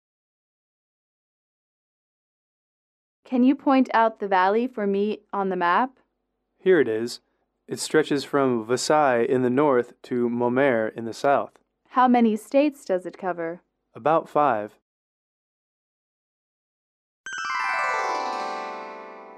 英语主题情景短对话11-1：山谷的位置(MP3)